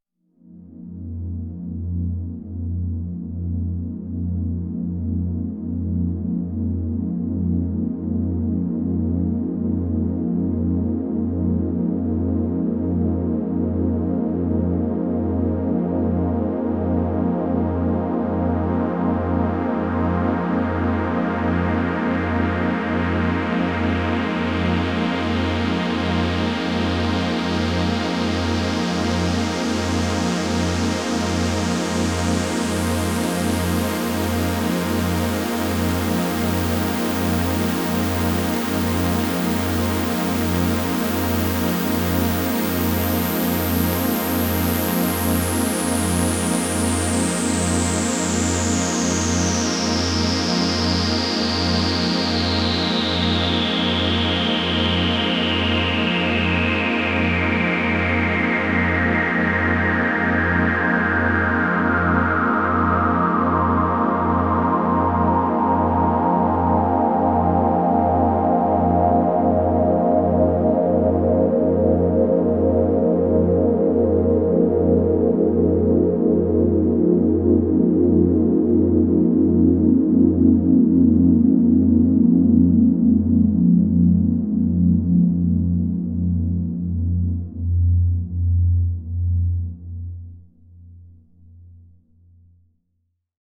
Спойлер: сравнение звука VS-1 и OB Баловались в другой теме Это на обэшке я попробовал сделать то, что он s 11:10 играет.